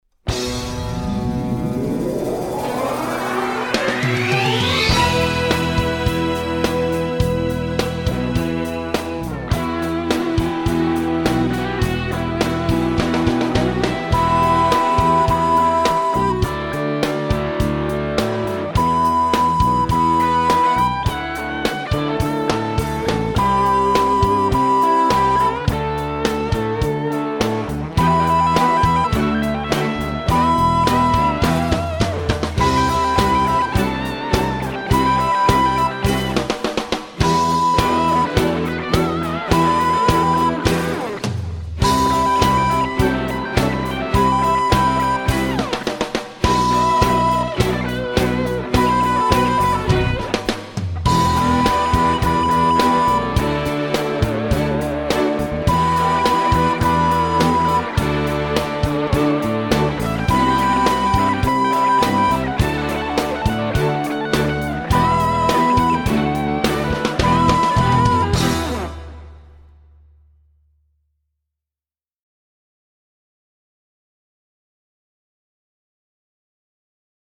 Voicing: Recorder C